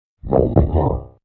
Boss_COG_VO_statement.mp3